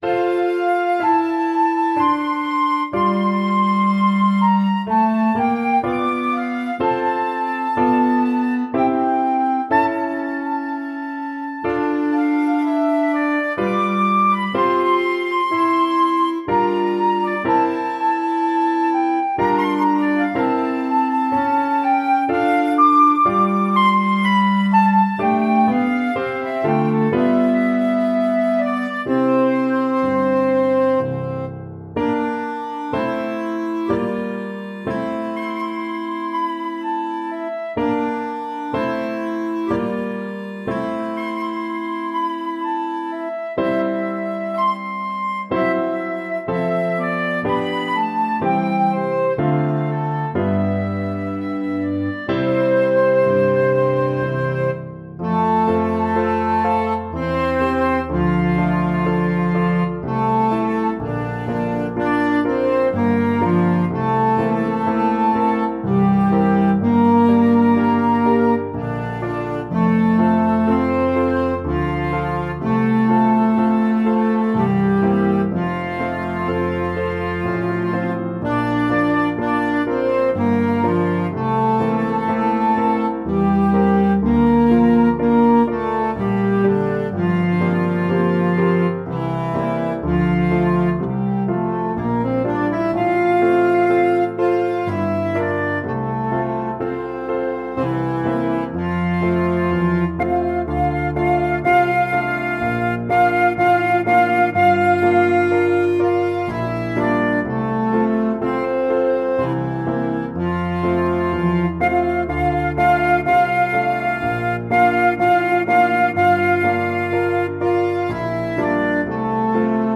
This intermezzo is very beautiful and poignant.
Piano Trio
» 442Hz